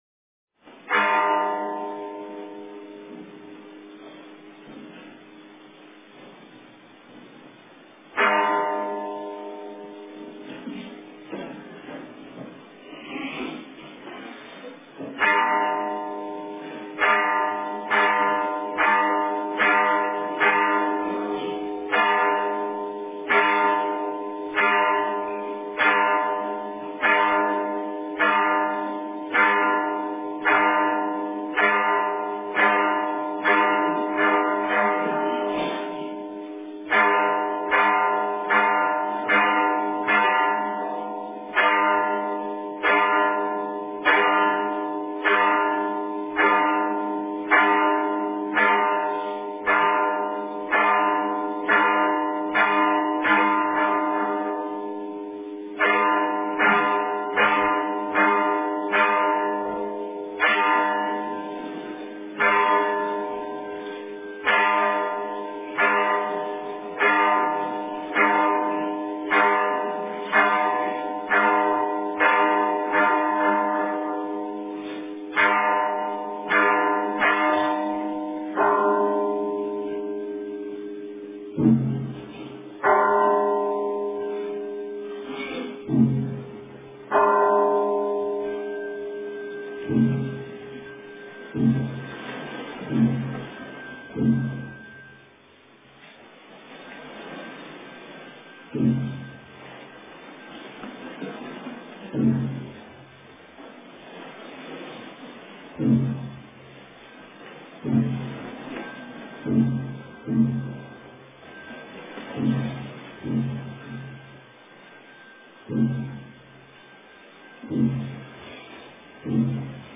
早课--福鼎平兴寺 经忏 早课--福鼎平兴寺 点我： 标签: 佛音 经忏 佛教音乐 返回列表 上一篇： 山僧--佚名 下一篇： 早课--圆光佛学院男众 相关文章 佛说阿弥陀经--如是我闻 佛说阿弥陀经--如是我闻...